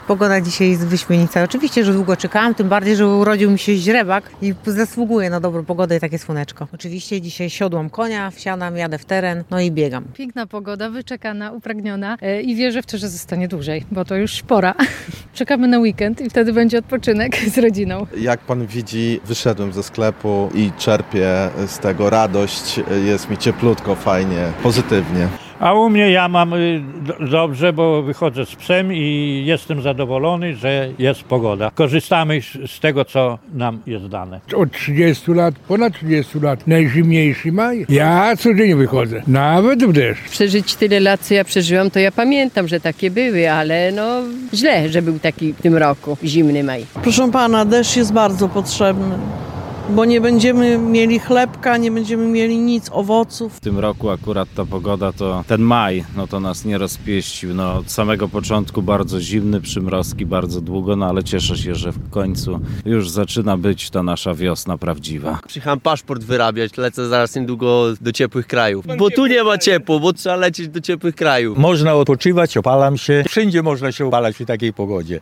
Sonda: Łomża: Mieszkańcy tęsknili za ładną pogodą
Starsi mieszkańcy miasta w rozmowie z Radiem Nadzieja nie kryli, że nie pamiętali równie kiepskiej aury w tym miesiącu.
Zapraszamy do wysłuchania opinii mieszkańców z Łomży o wyczekiwanej majowej poprawie pogody: